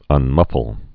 (ŭn-mŭfəl)